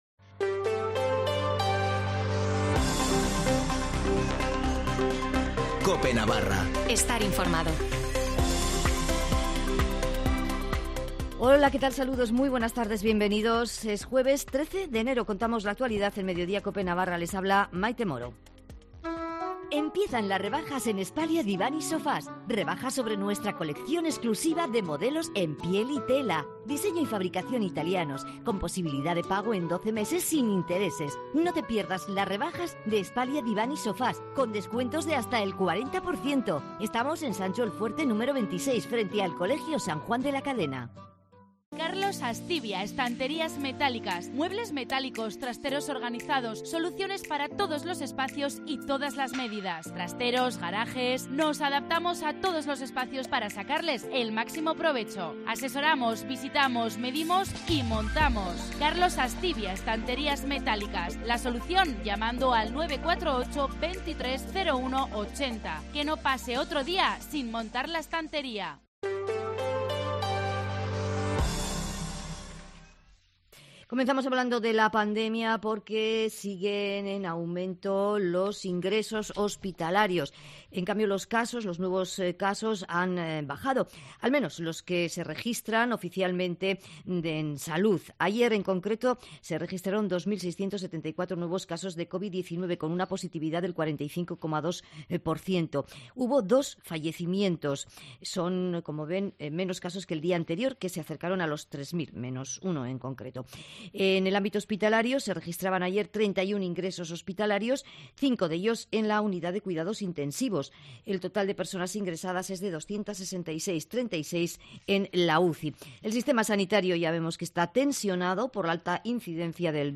Informativo de las 14:20 en Cope Navarra (13/01/2022)